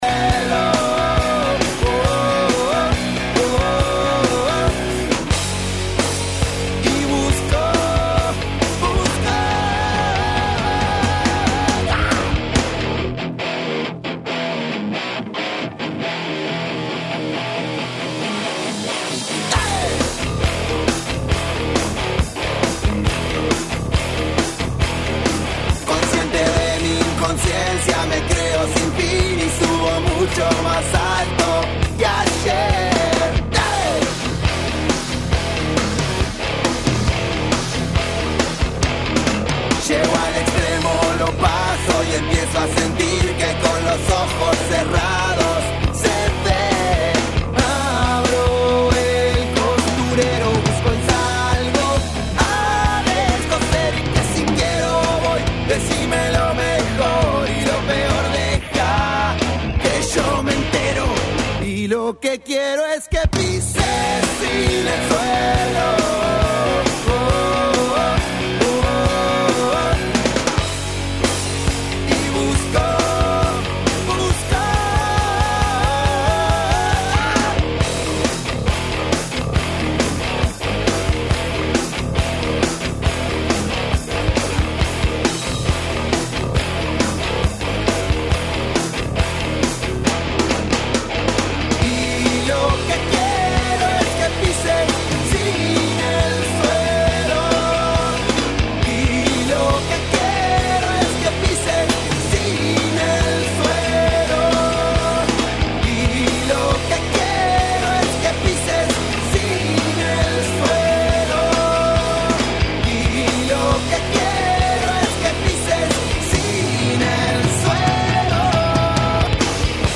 Esta tarde, habló en el programa mundialista de RN Radio «Sobre la Hora» y analizó la derrota de Argentina con Arabia Saudita en su debut en Qatar.